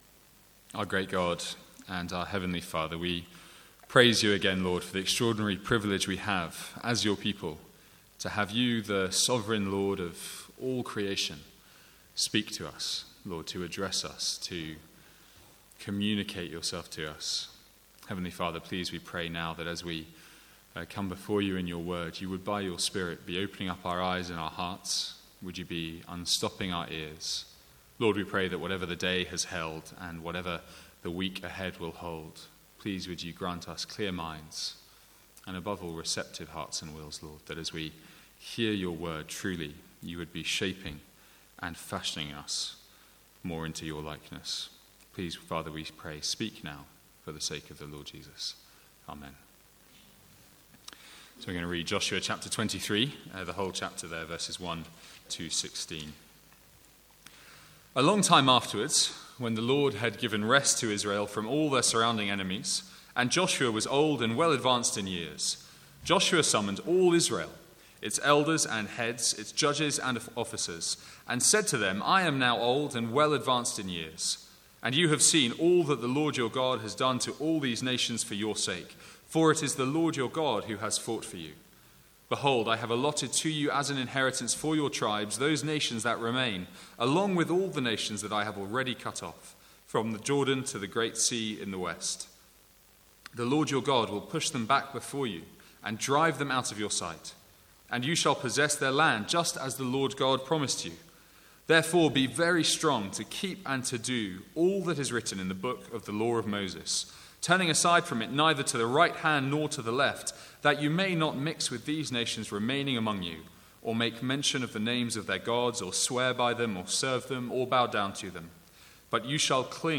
From the Sunday evening series in Joshua.